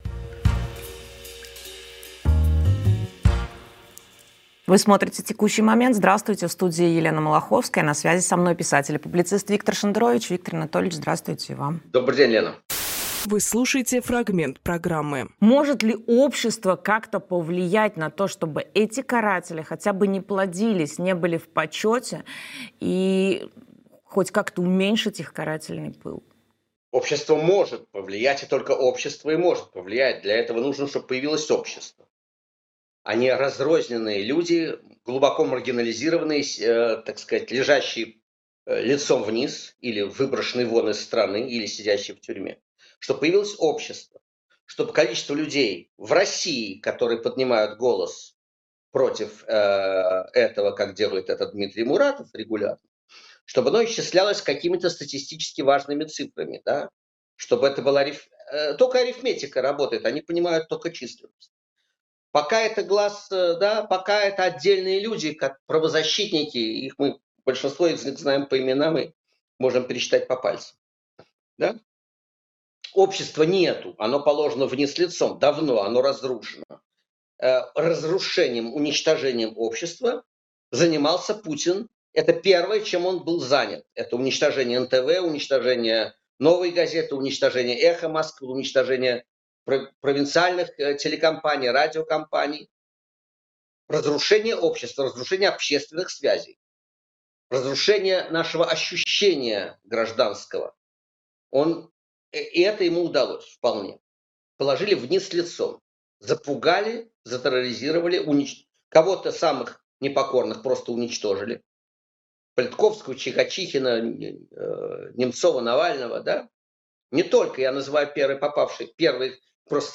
Виктор Шендеровичписатель
Фрагмент эфира от 27.05.25